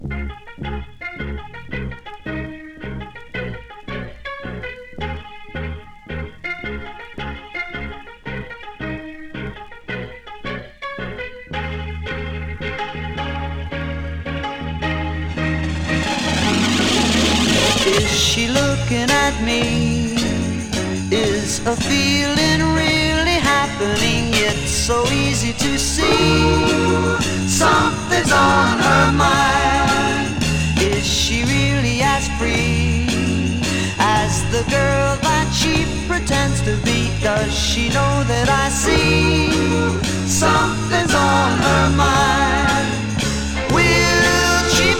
Rock, Pop, Psychedelic Rock　USA　12inchレコード　33rpm　Stereo